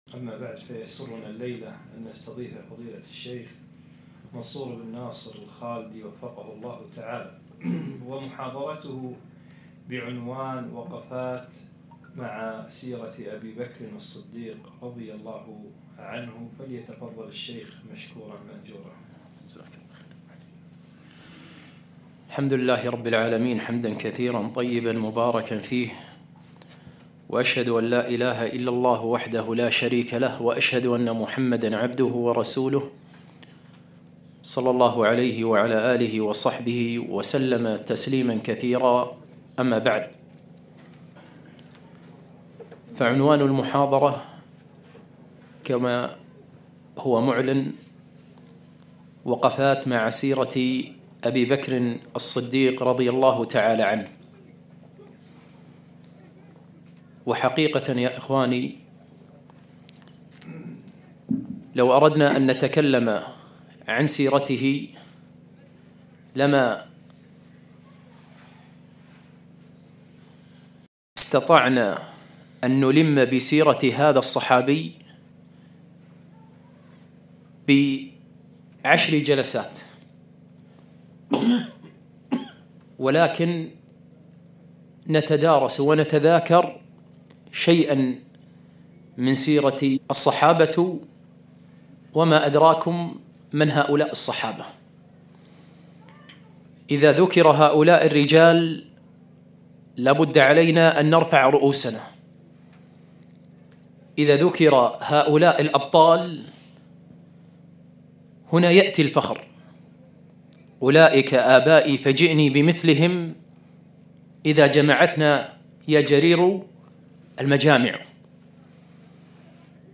محاضرة - وقفات مع سيرة أبي بكر الصديق - رضي الله تعالى عنه